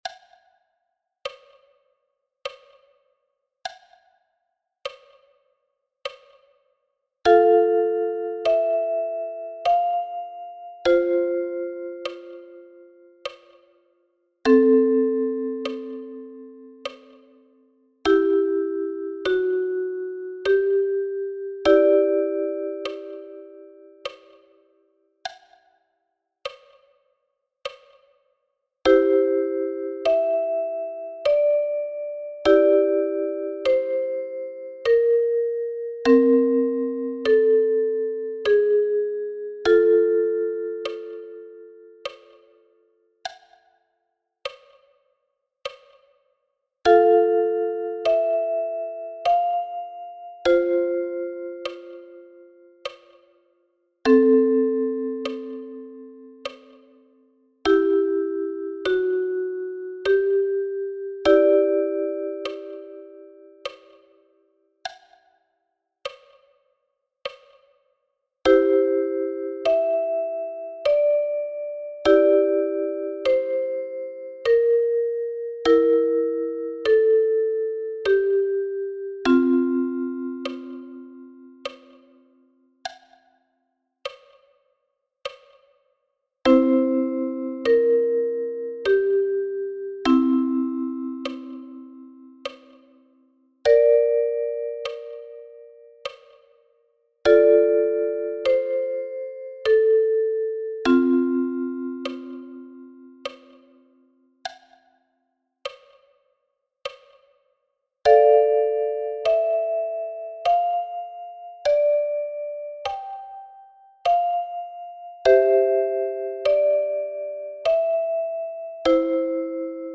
41 Bearbeitungen aus der traditionellen Musik